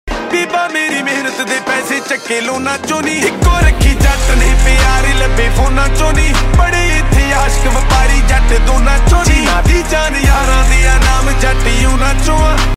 Set this powerful Punjabi beat as your caller tune.